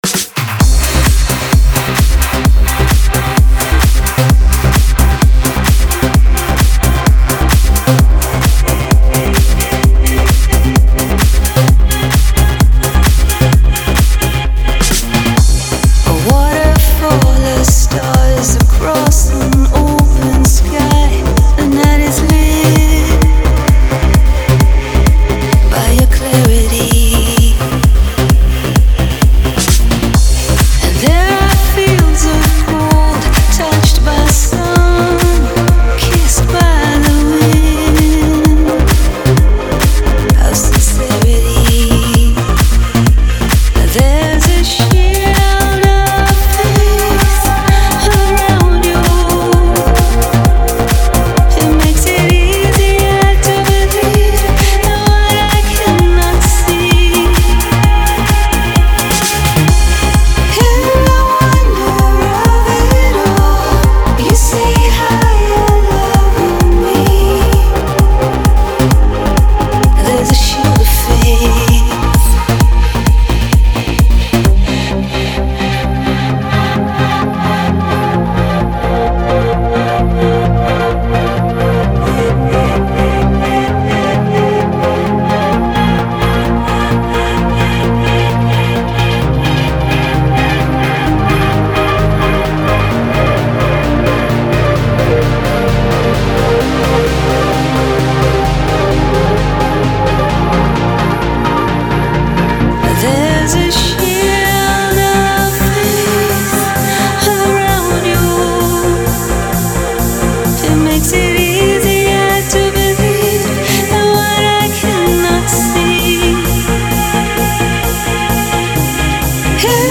Категория: Электро музыка » Транс